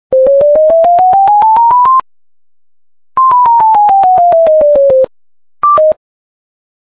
Chromatic Scale